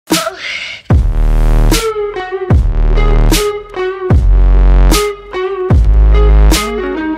When the bass drops… (small sound effects free download